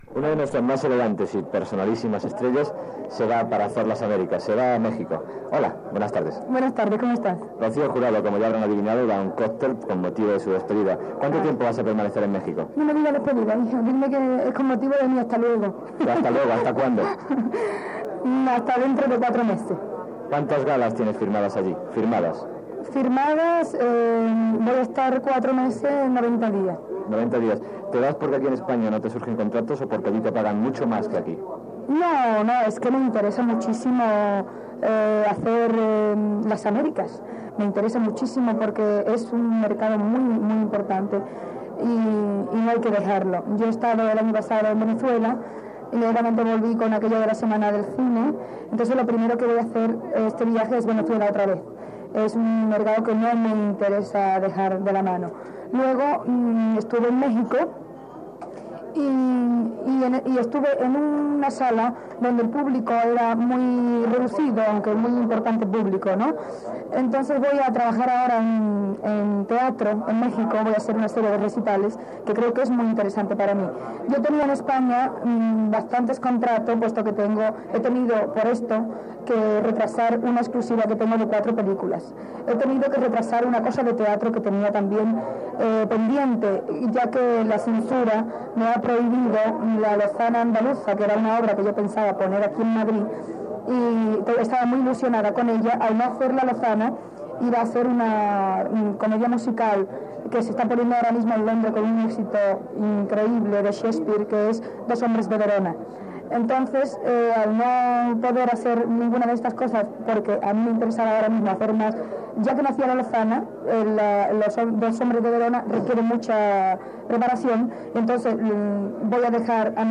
Entrevista a la cantant Rocío Jurado abans de marxar cap a Mèxic per actuar-hi durant uns mesos i a un responsable de la seva casa discogràfica